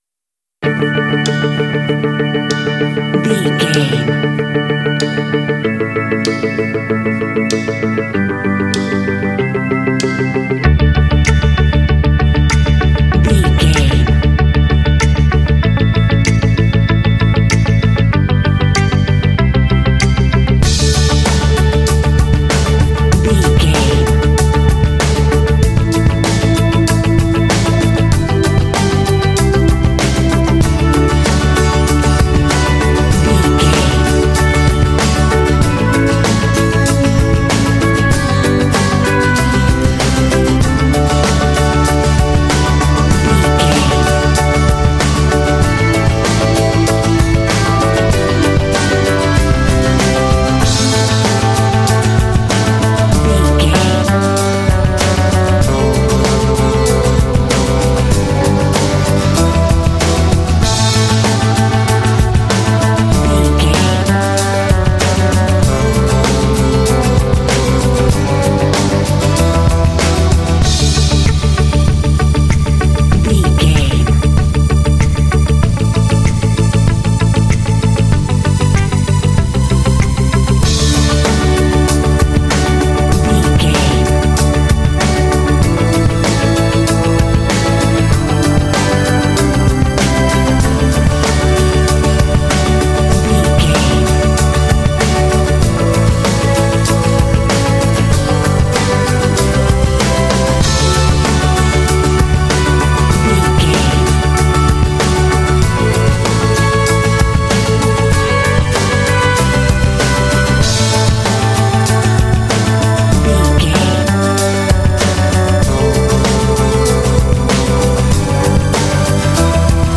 Uplifting
Mixolydian
dramatic
motivational
inspirational
electric guitar
drums
bass guitar
percussion
strings
acoustic guitar
synthesiser
brass
contemporary underscore
indie